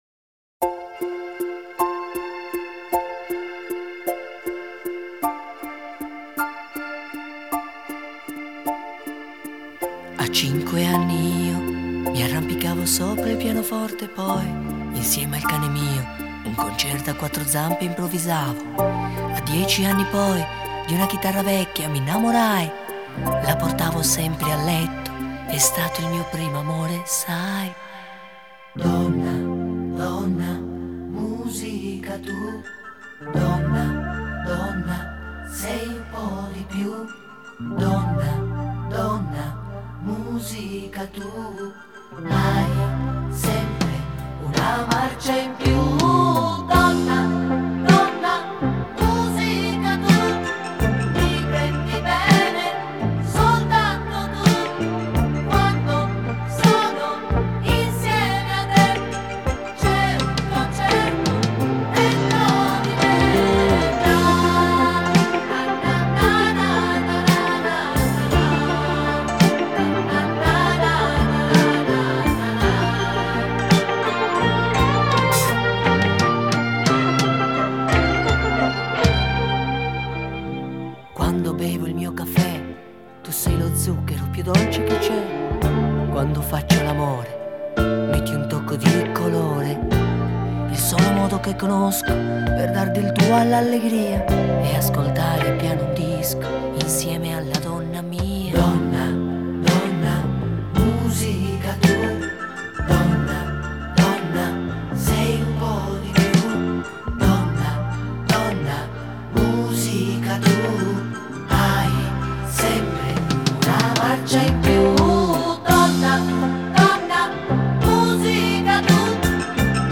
Итальянская эстрада